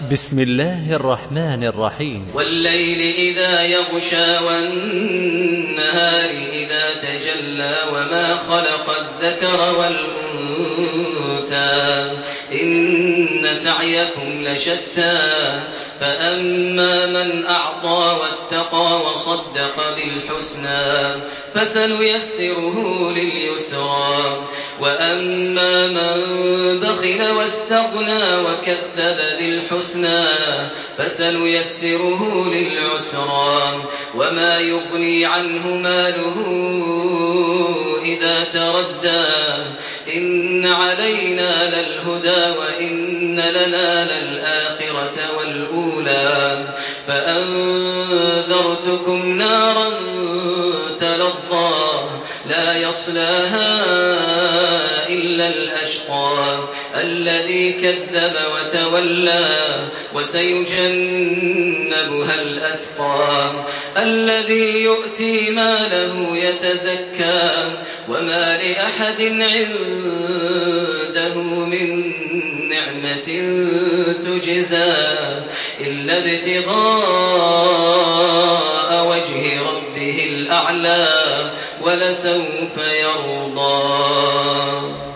Makkah Taraweeh 1428
Murattal